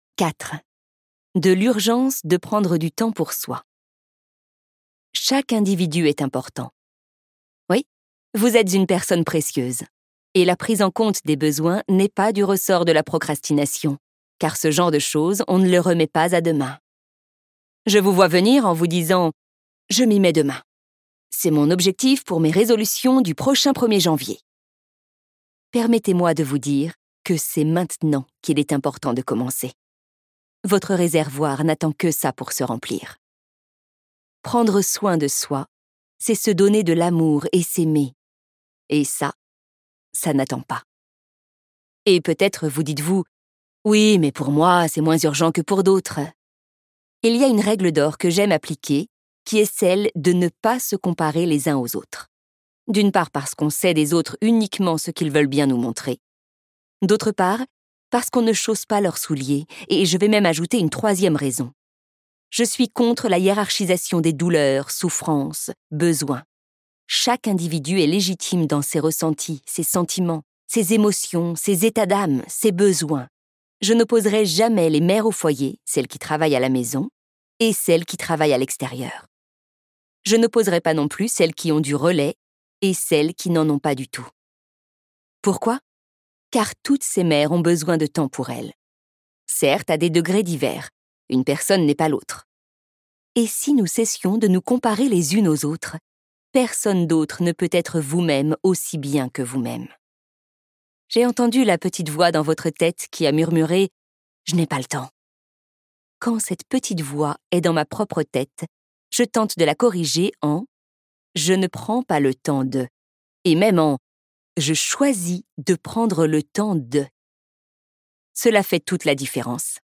Des rituels simples, courts, réalisables partout, pour prendre soin de vous dans une vie bien occupée par un (ou plusieurs) enfant(s).Ce livre audio est interprété par une voix humaine, dans le respect des engagements d'Hardigan.